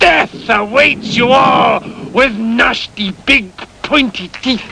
Sound Effects for Windows
death.mp3